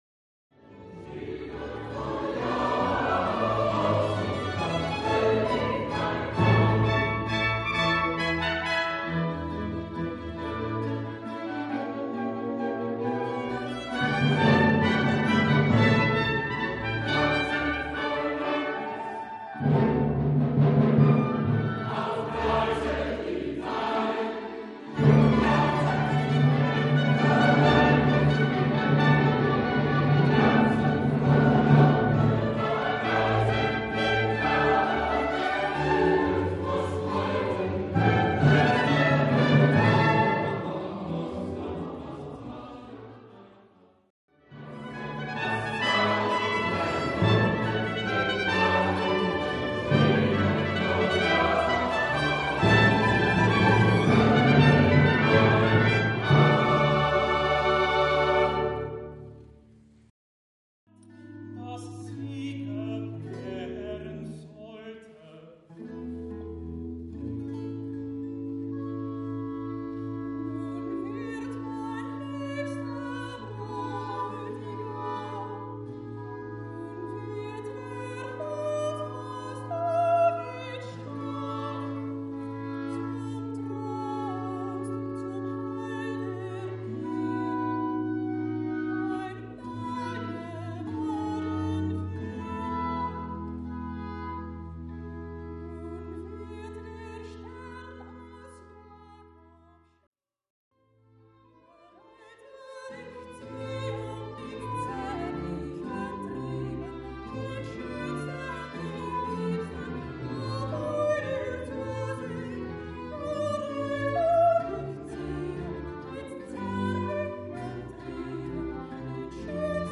delen 1,2,3,5 en 6 volgens de selectie van Albert Schweitzer mmv koor, solisten en orkest.
Kapel
collage van het eerste deel in Deurne